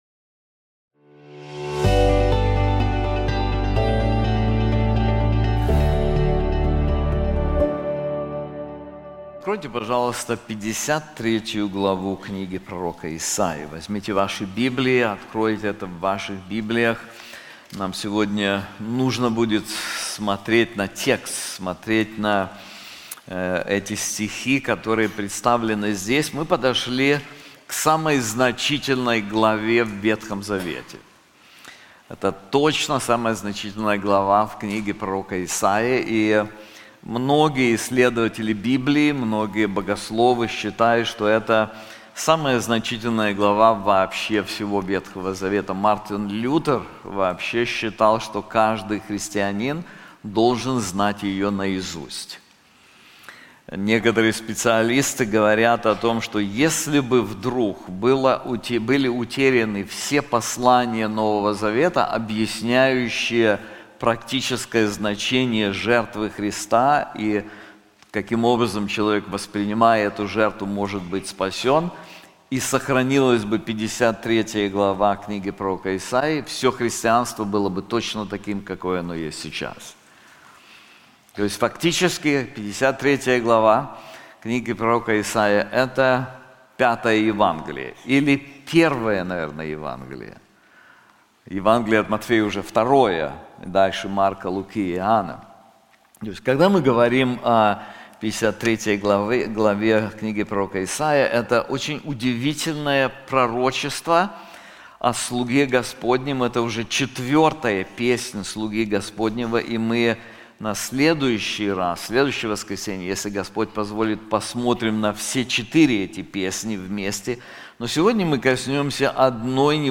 This sermon is also available in English:Israel’s Repentance • Isaiah 53:1-3